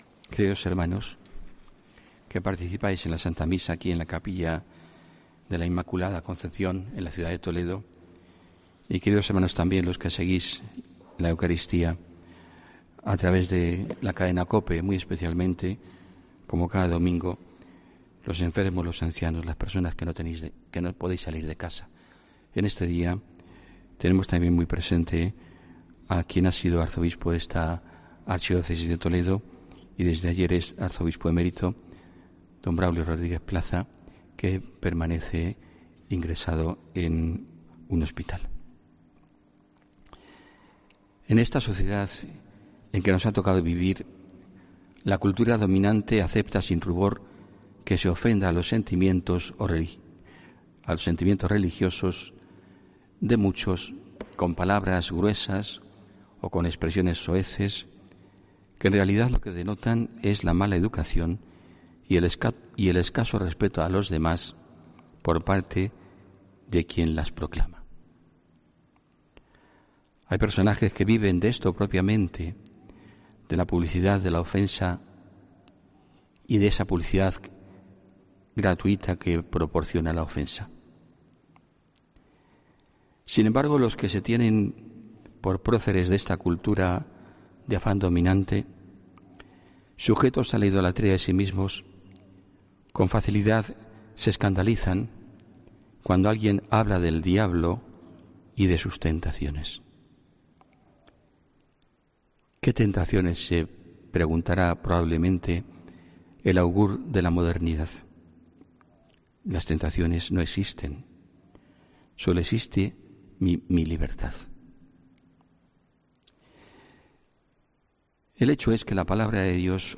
Homilía